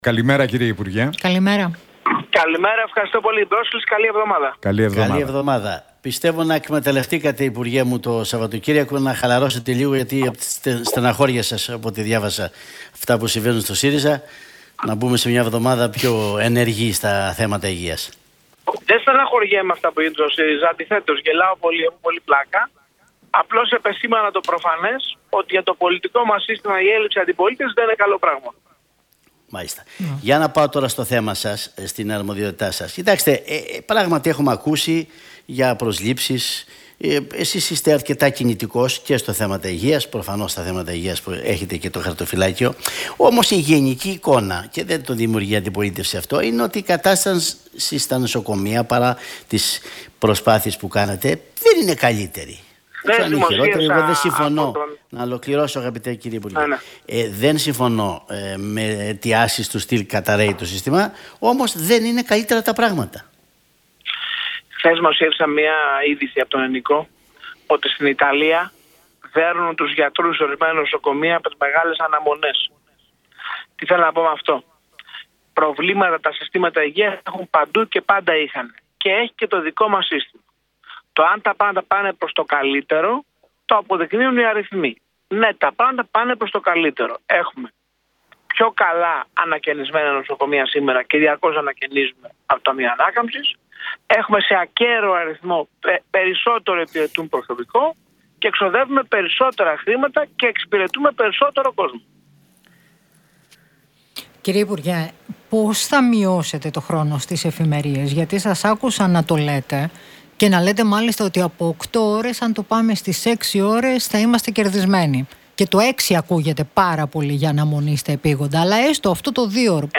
Γεωργιάδης στον Realfm 97,8: Μέσα στον Οκτώβριο το χρονοδιάγραμμα των μέτρων για να μειωθεί ο μέσος χρόνος αναμονής στις εφημερίες